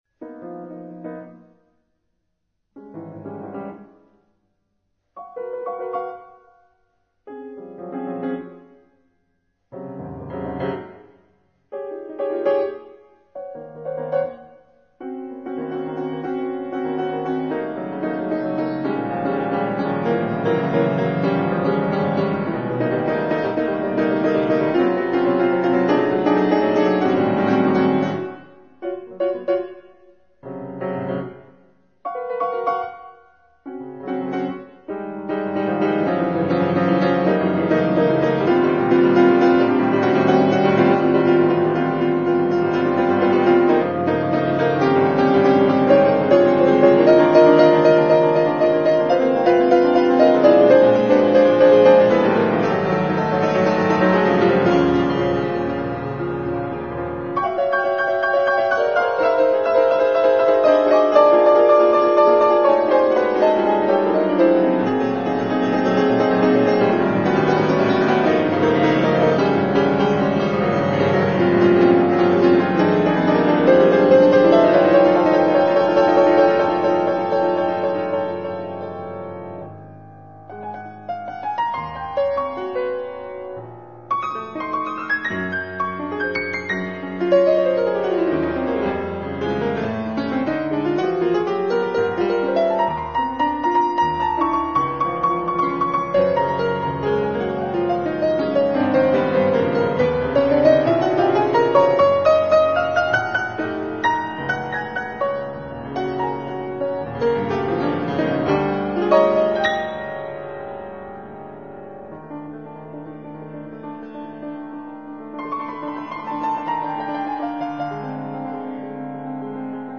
Klavierwerke